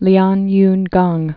(lyänyngäng, -yün-)